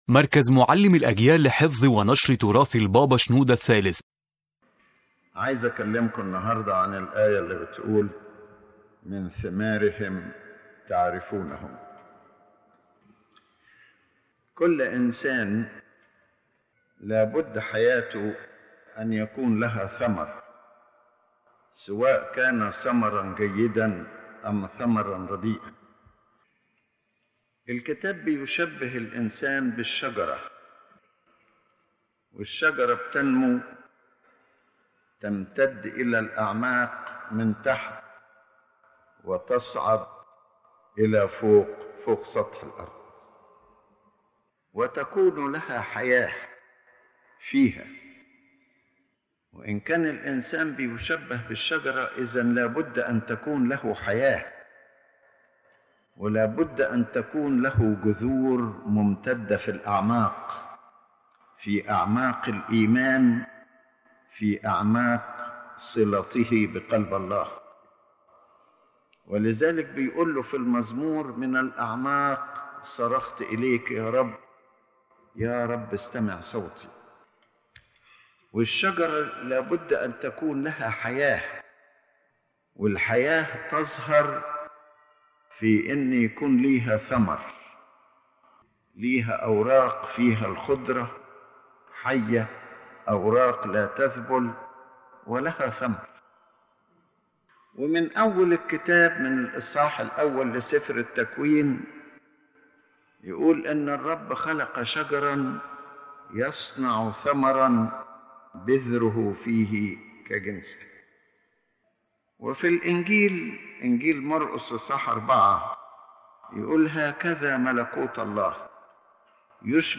The lecture confirms that a person’s spiritual life is measured by its fruits, because every person is like a tree, and this tree must show its life through fruit.